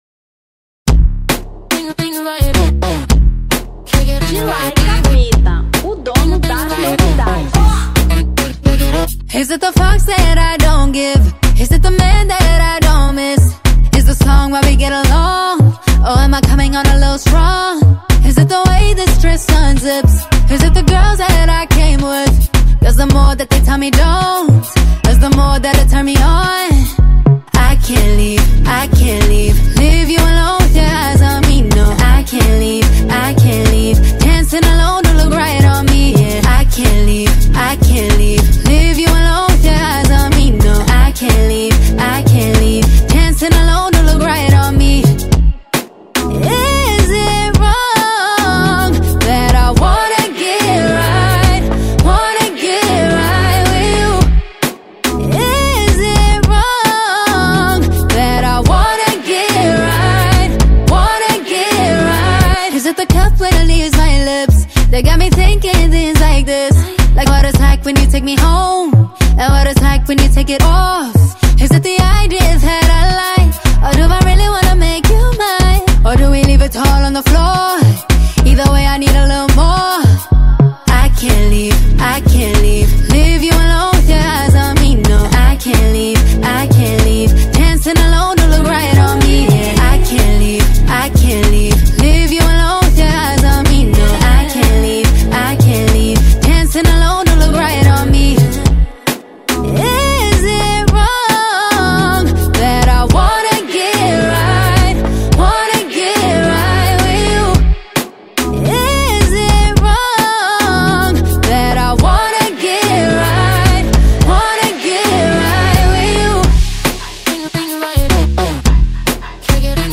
Afro Beat 2025